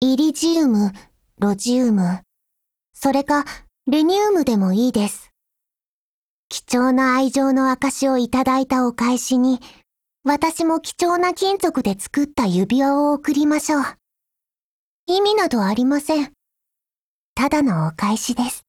贡献 ） 协议：Copyright，其他分类： 分类:少女前线:史蒂文斯520 、 分类:语音 您不可以覆盖此文件。
Stevens520_DIALOGUEWEDDING_JP.wav